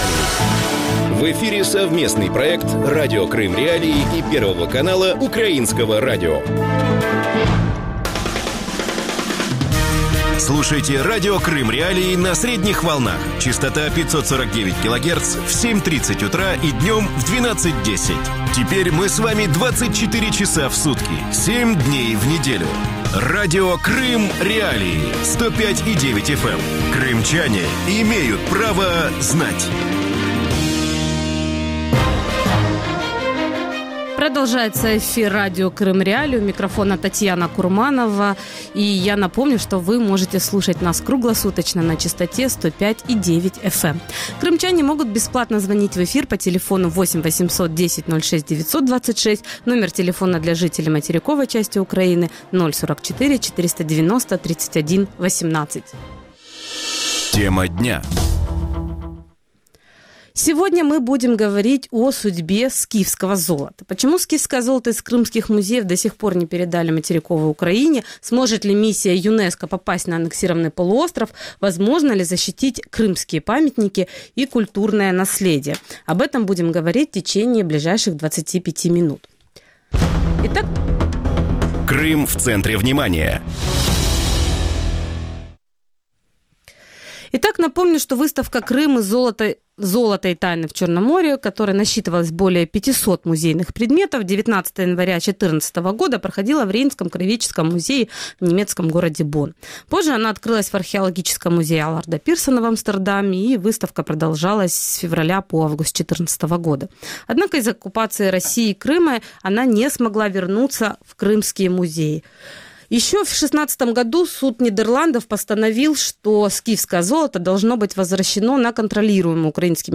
Радио Крым.Реалии вещает 24 часа в сутки на частоте 105.9 FM на северный Крым.